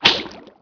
meleemiss1.wav